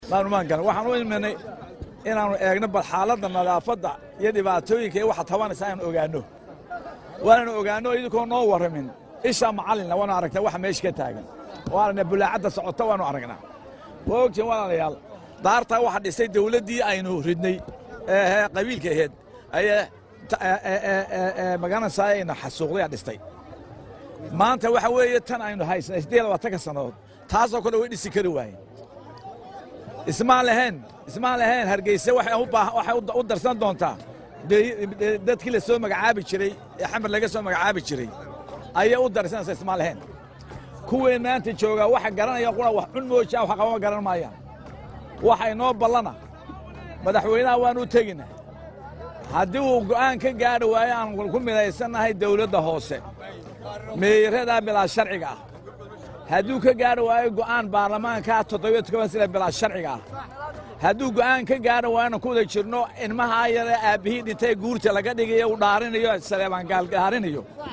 Faysal Cali Waraabe waxaa uu hadalkan sheegay xilli maanta uu booqasho ku tegay suuqa weyn ee magaalada Hargeysa, oo nadaafad xumo baahsan ay ka jirto.
Hoos ka dhageyso codka Faysal Cali Waraabe